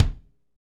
Index of /90_sSampleCDs/Northstar - Drumscapes Roland/DRM_Funk/KIK_Funk Kicks x
KIK FNK K0EL.wav